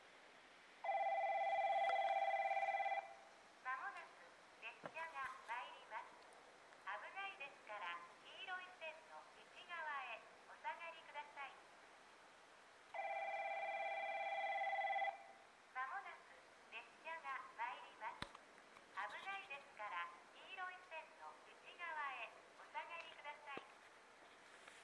この駅では接近放送が設置されています。
１番のりば日豊本線
接近放送普通　南宮崎行き接近放送です。